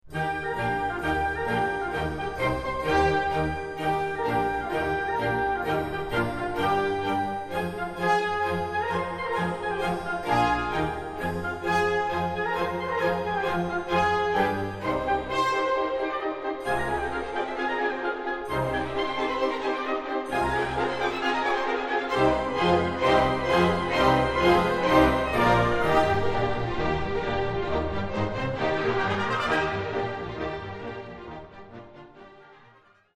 Ballet Music